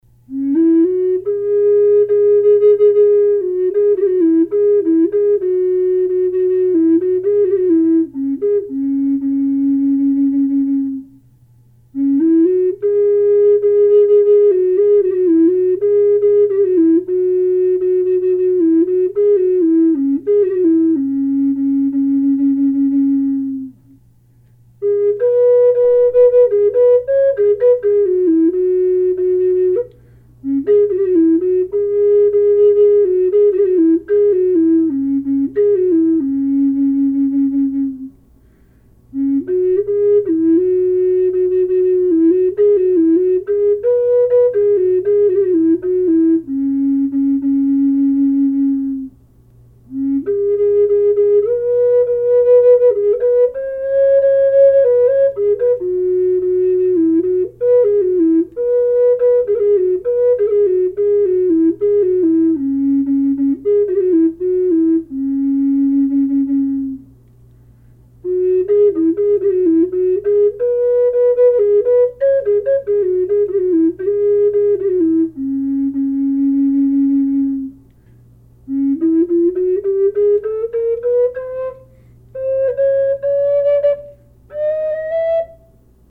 Aspen low C# minor
Dry, no effect and
aspen-low-c-sharp.mp3